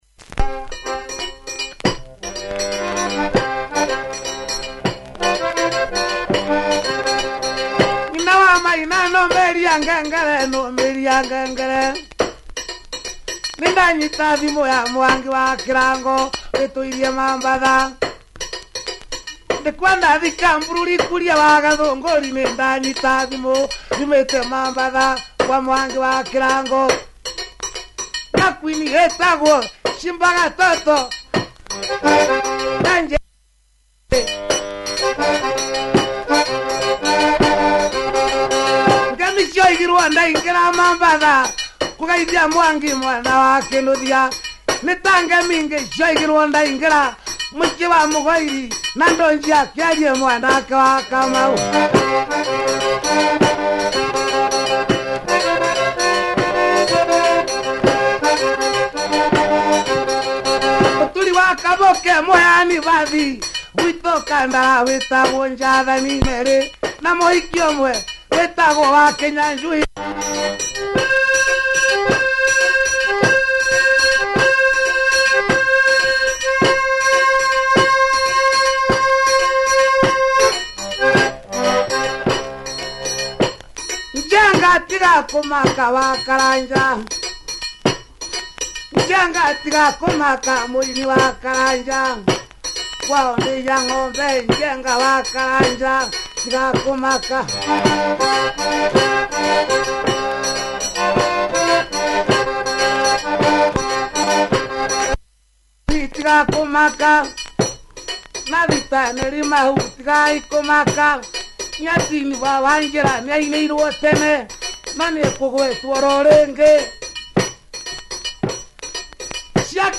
Disc has some warp. https